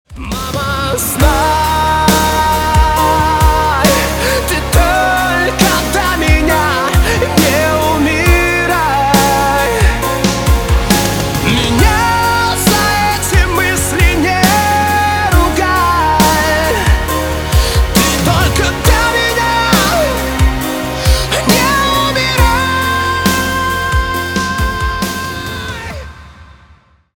на русском громкие на маму про любовь